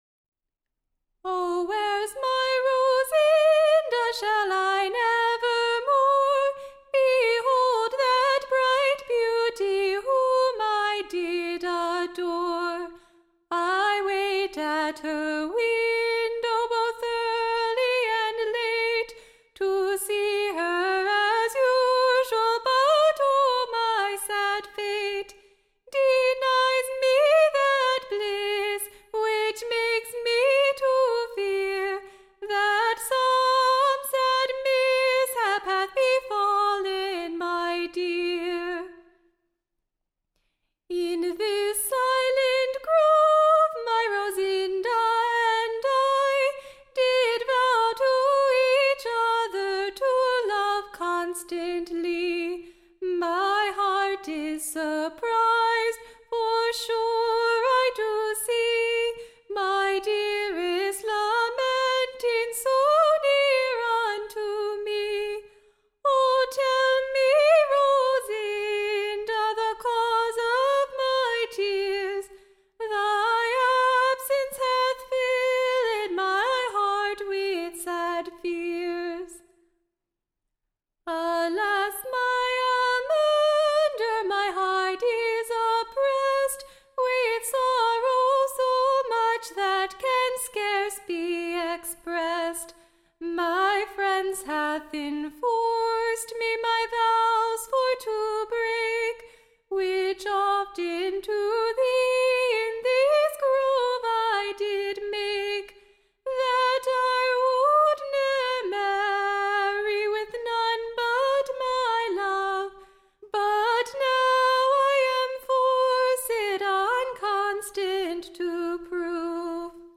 Recording Information Ballad Title An Excellent New Song, Call'd, / The Unkind Parents, / OR, / The Unfortunate Lovers.